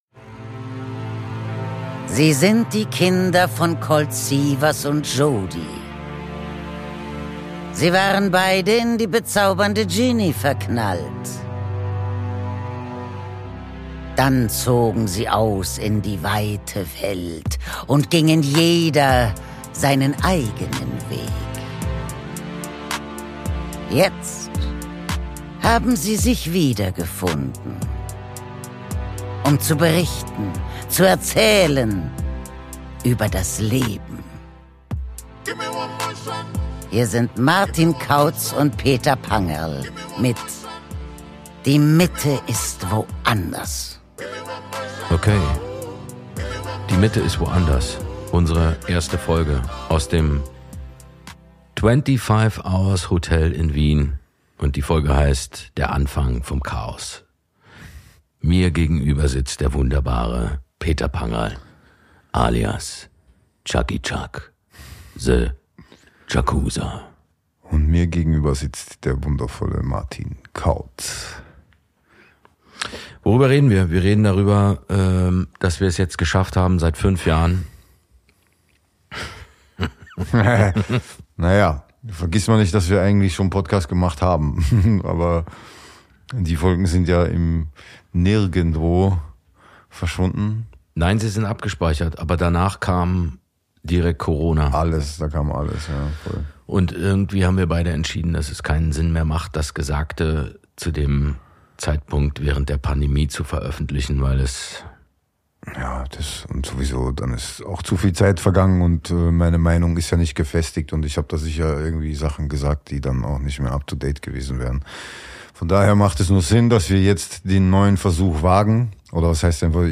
In Folge#1 sprechen die beiden über ihre Freundschaft, das Leben wie es sich gerade anfühlt und das was noch erlaubt ist und nicht stört. Es wird geflucht, es wird auseinandergenommen und es wird gelacht.